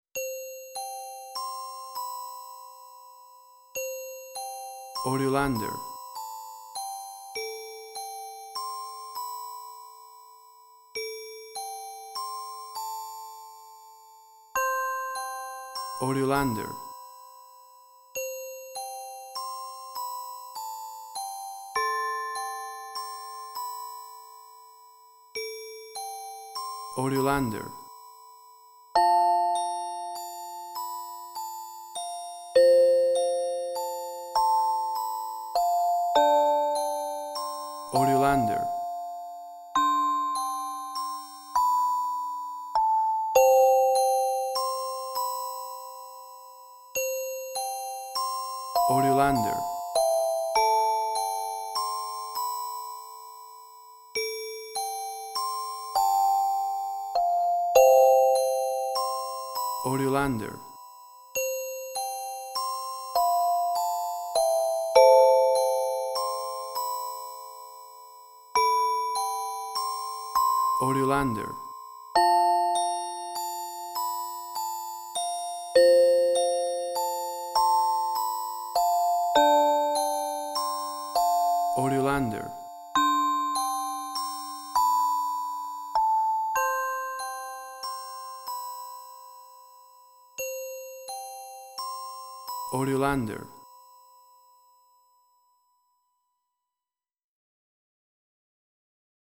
Piano Mecanique.jpg
Indie Quirky
Tempo (BPM): 100